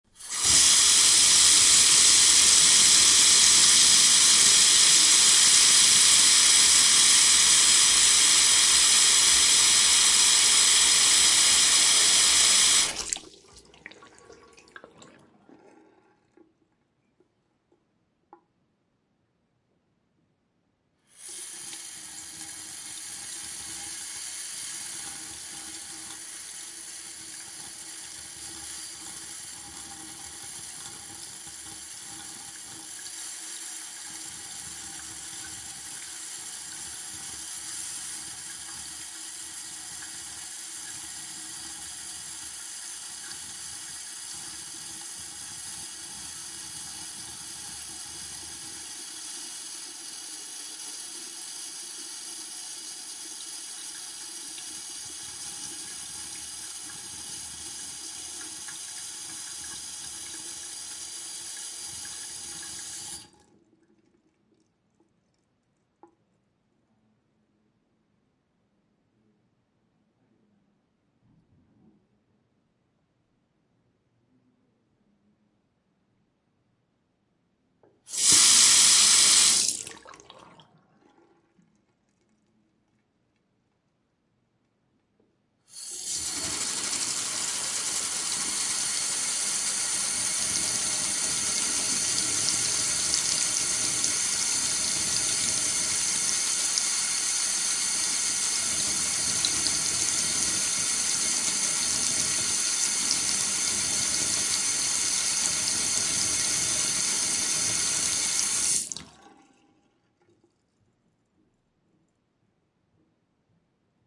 随机的 "卫生间水槽附近的流水"。
描述：浴室水槽附近运行水各种各样.flac
Tag: 运行 浴室